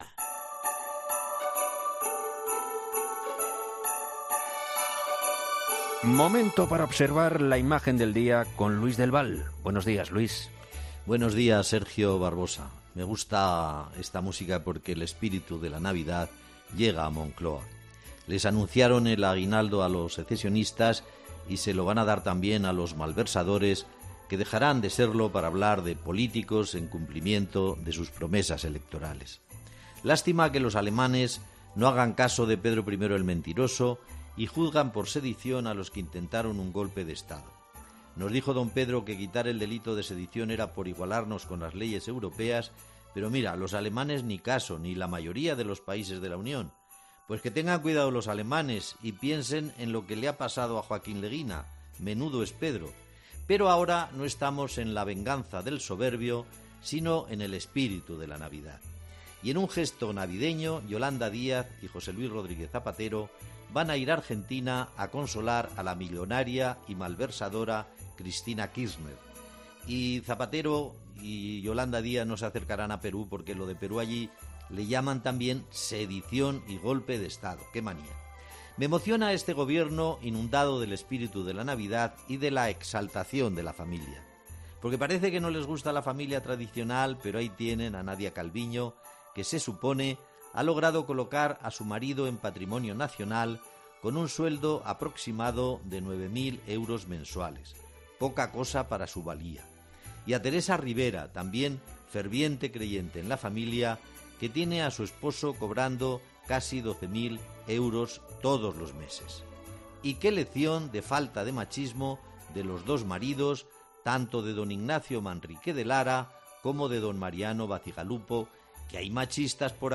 Habla el profesor de la situación política actual de nuestro país, la reforma de la malversación y la inestabilidad política en Perú tras la detención de Pedro Castillo